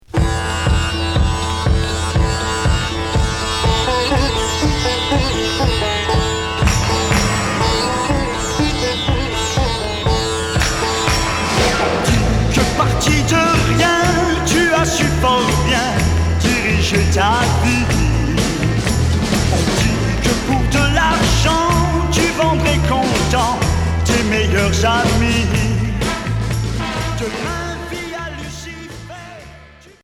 Chanteur 60's EP au sitar